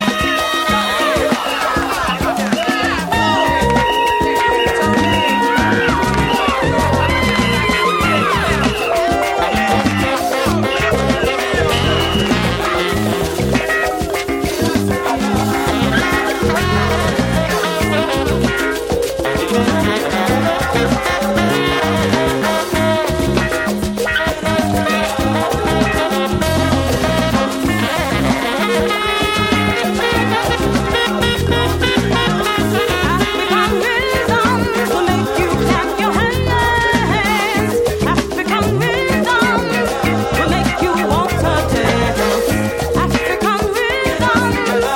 which is well-known as a rare groove classic